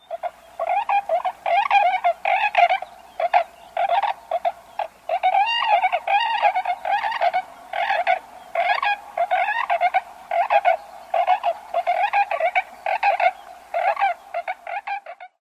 小天鹅叫声